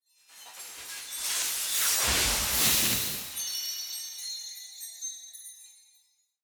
UI_Point_BoneText_Vanish.ogg